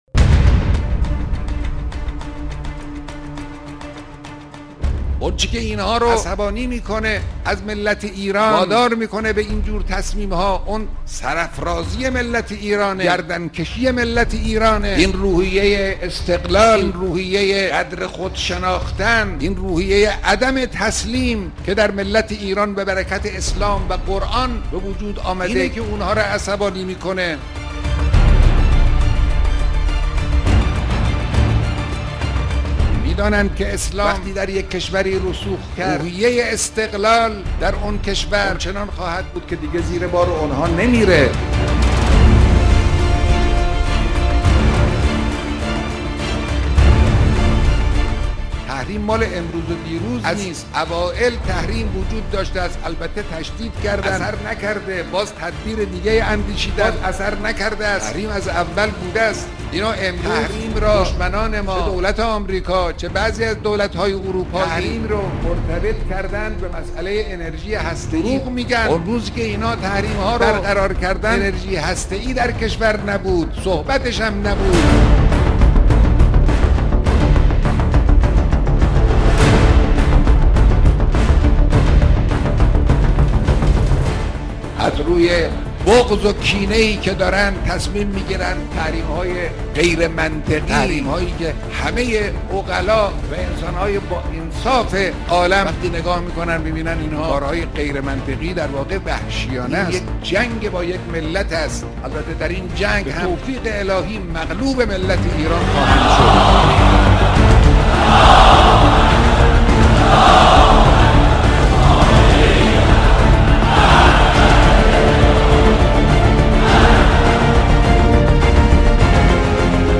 رهبر معظم انقلاب در اولین سخنرانی سفر خود به خراسان شمالی در جمع مردم بجنورد سخنان کلیدی و راهگشایی را در خصوص تحریم ها و وقایع روزهای گذشته در خصوص نوسانات بازار بیان داشتند.